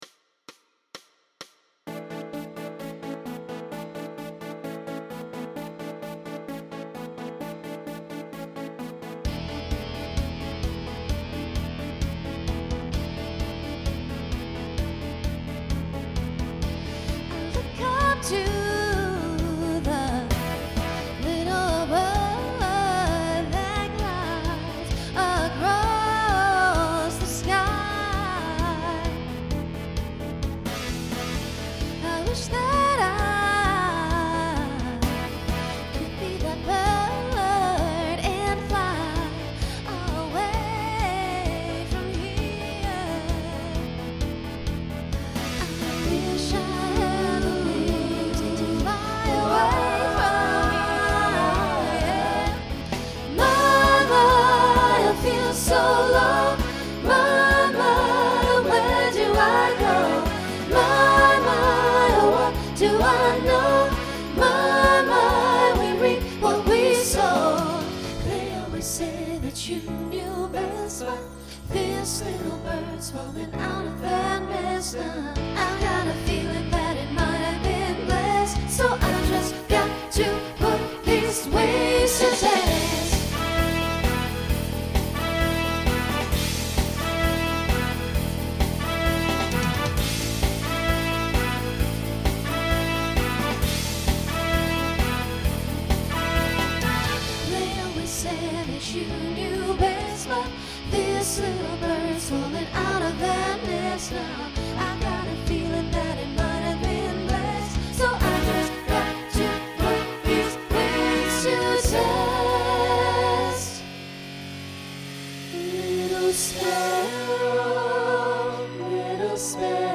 Voicing SATB Instrumental combo Genre Folk , Rock